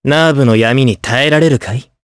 Evan-Vox_Skill6_jp_b.wav